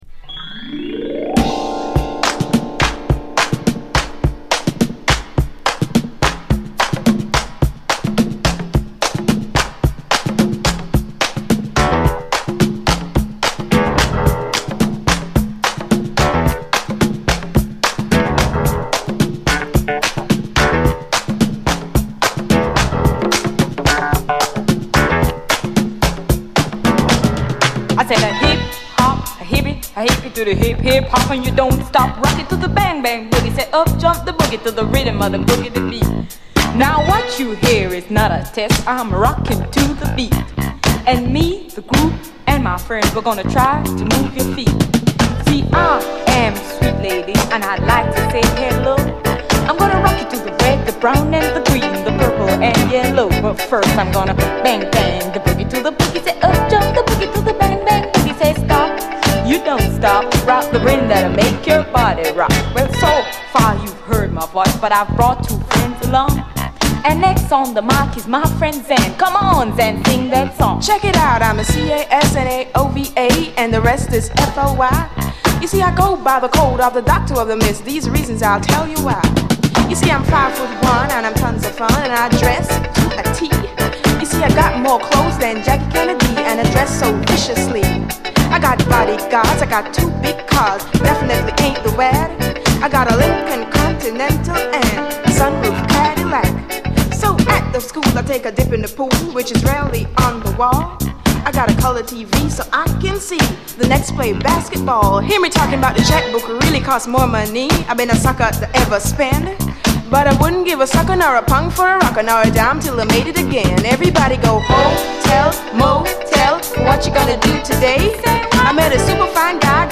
SOUL, 70's～ SOUL, DISCO, REGGAE, HIPHOP
両面最高、めちゃくちゃカッコいいです。どちらも後半はインストへと接続。